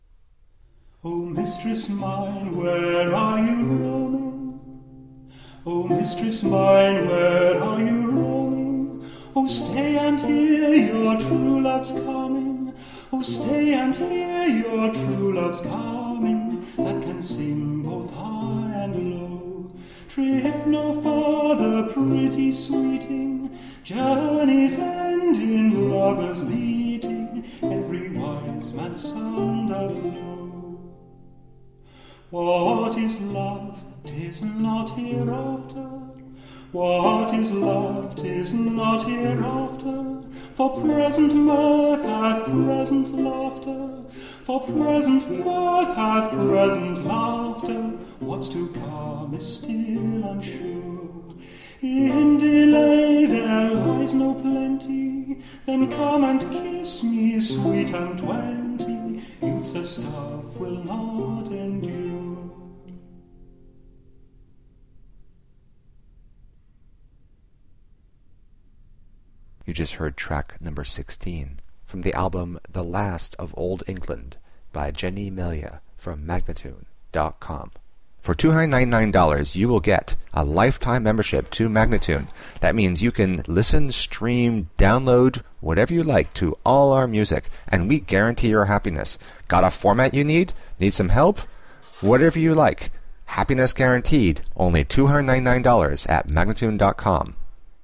Elizabethan lute and folk song.
Classical, Renaissance, Classical Singing
Lute